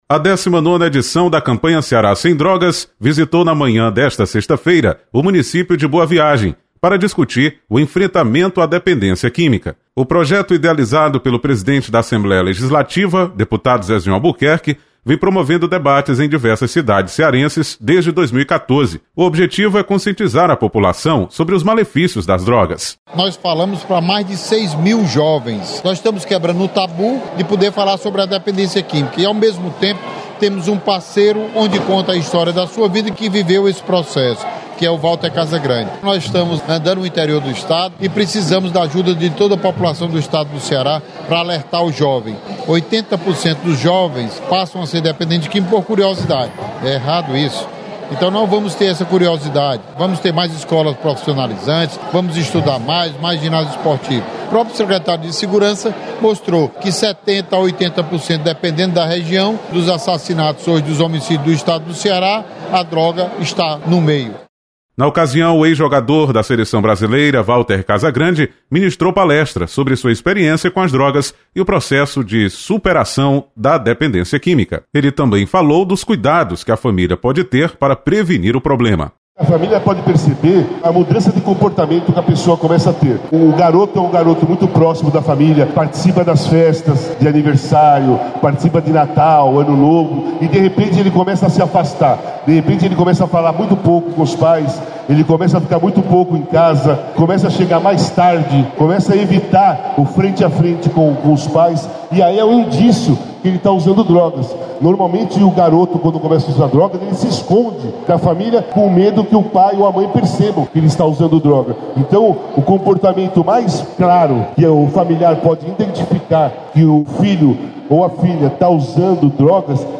Campanha Ceará sem Drogas visita o município de Boa Viagem nesta sexta-feira. Repórter